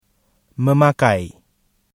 memakai（memakéy） 発 （使う：二重母音：口語）